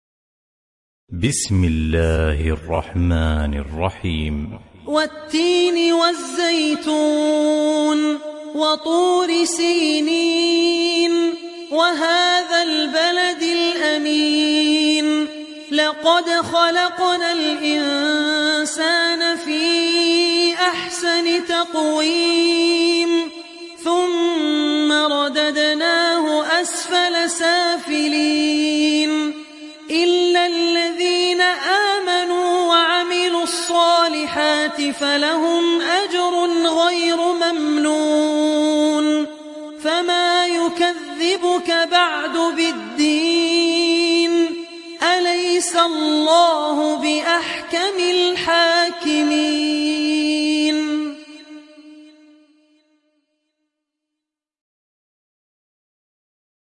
Sourate At Tin Télécharger mp3 Abdul Rahman Al Ossi Riwayat Hafs an Assim, Téléchargez le Coran et écoutez les liens directs complets mp3